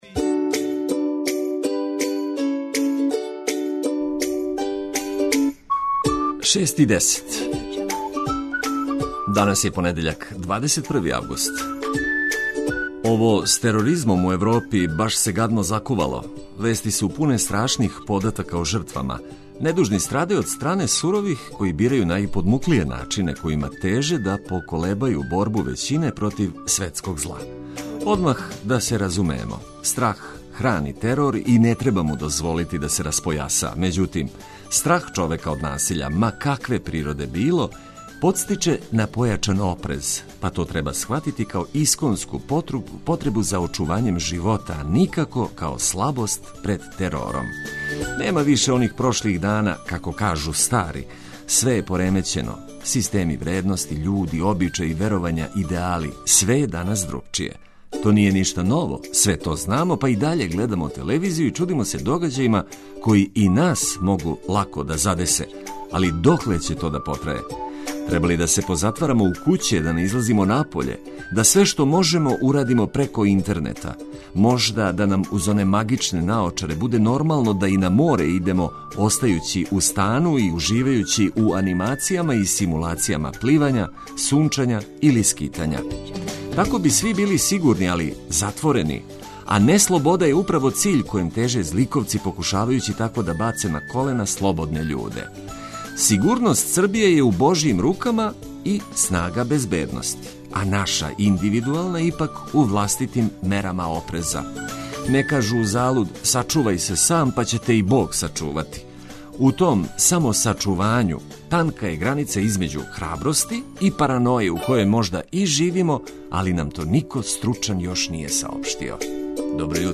Док трошимо прве сате новог дана сазнавајући шта има ново, наш пратилац биће сјајна музика за буђење и намигивање новим изазовима.